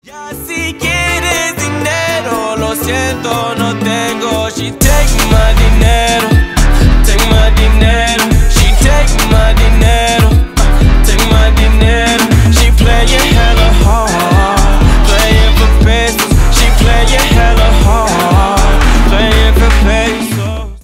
Поп Музыка # латинские # клубные